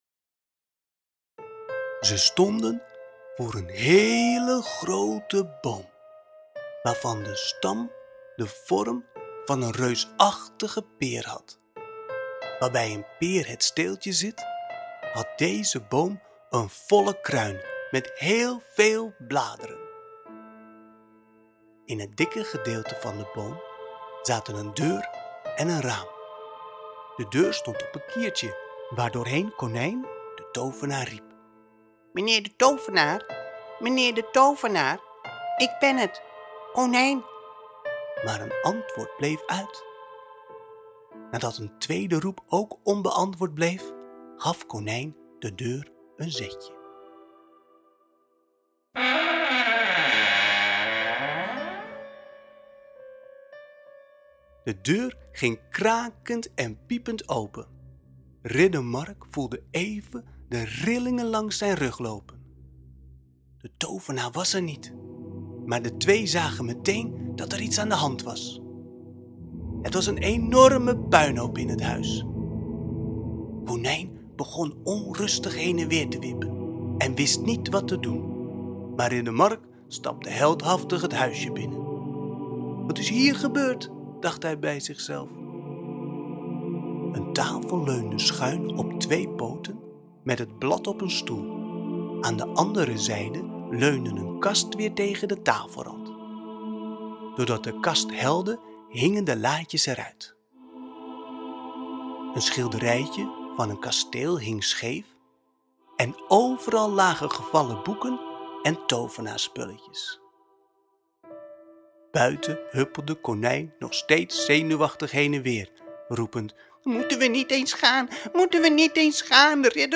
Ridder Mark combineert een prachtig geïllustreerd boek met magische muziek. De muziek is speciaal onder het luisterverhaal gecomponeerd en geproduceerd.